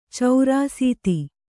♪ caurāsīti